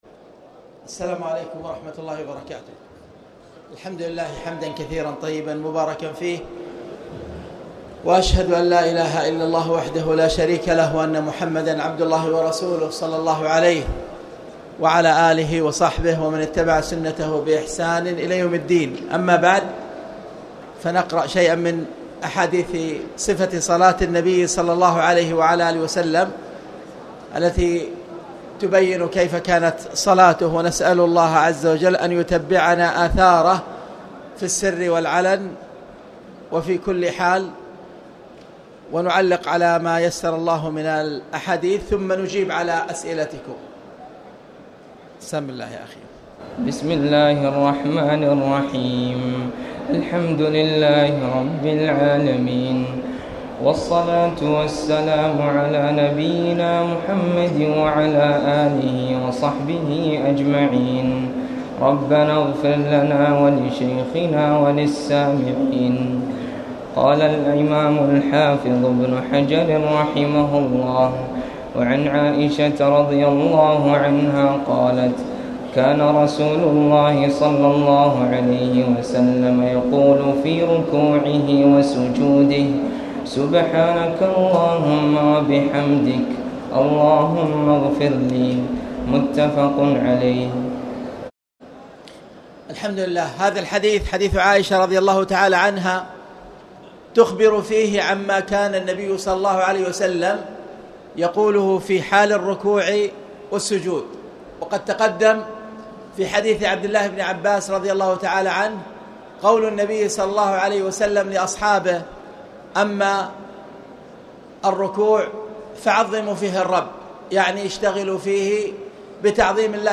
تاريخ النشر ١٥ رمضان ١٤٣٨ هـ المكان: المسجد الحرام الشيخ